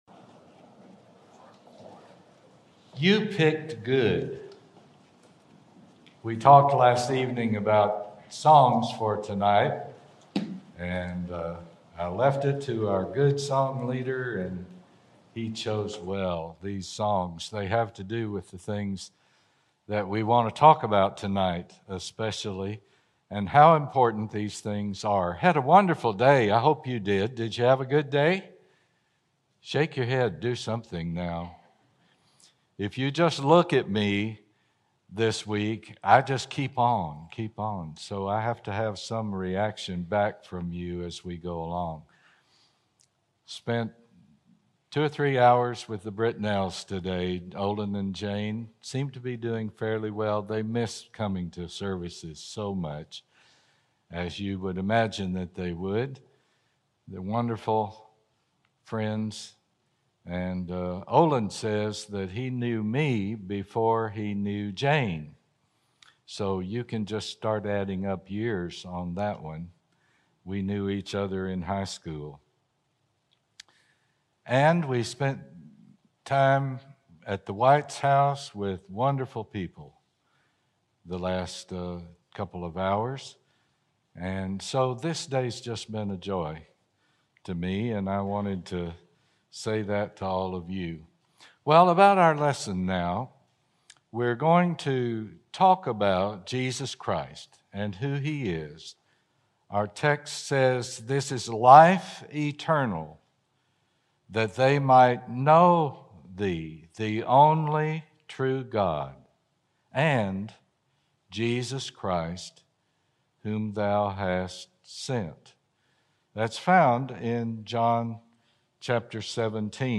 Part 3 of our 7-part gospel meeting